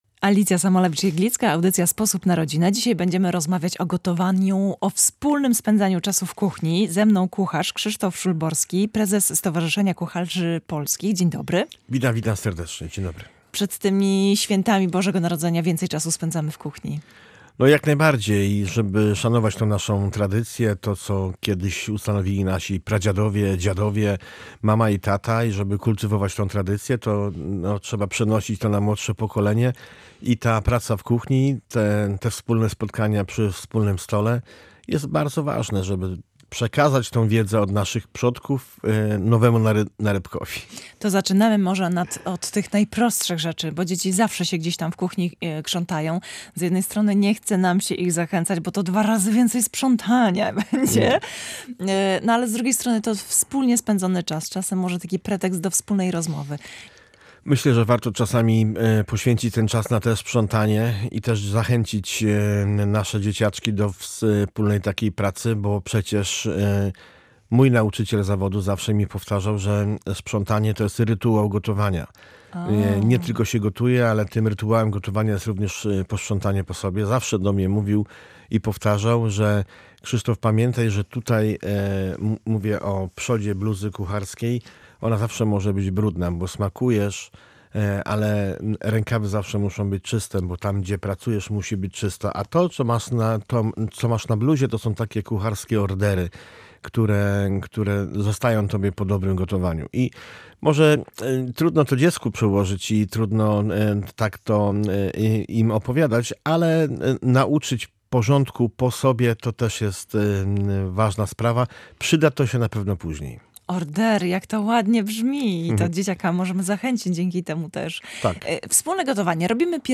rozmawiała o domowym gotowaniu, rodzinnych tradycjach i kuchennych przygotowaniach do świąt z kucharzem